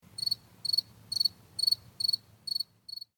crickets-DUJdcuUa.ogg